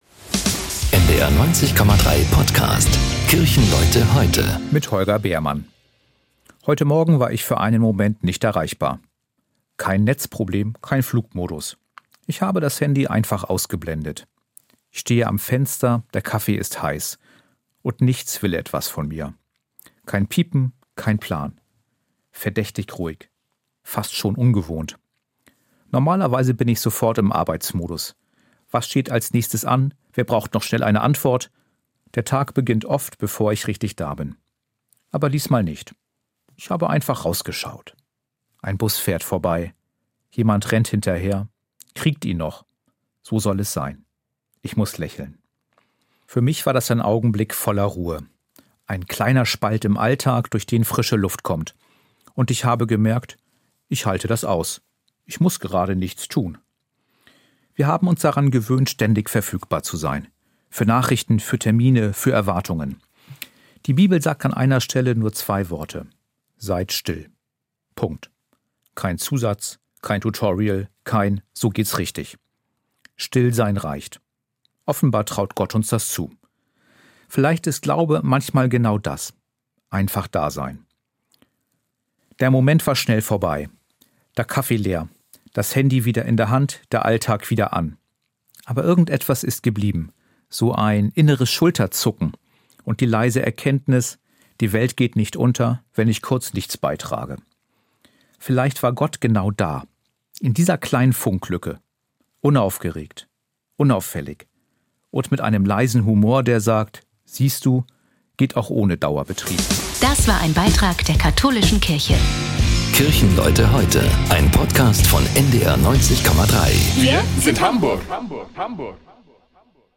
Hamburger Pastorinnen und Pastoren und andere Kirchenleute erzählen